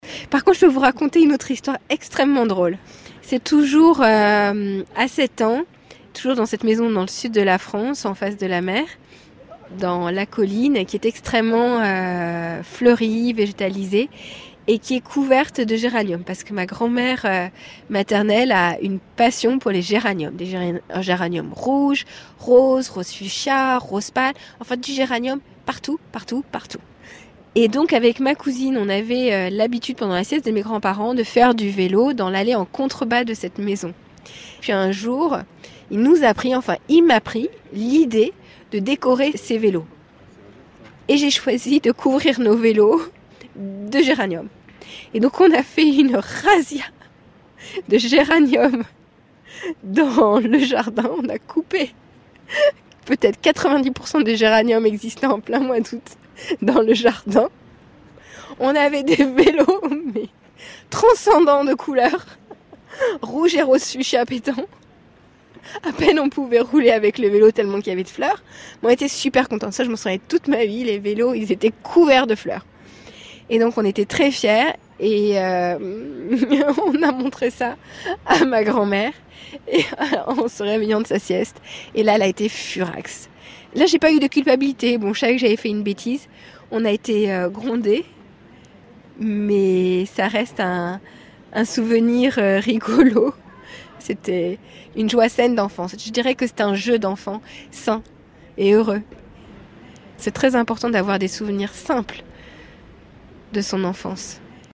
EXTRAIT ÉMISSION (RNS2)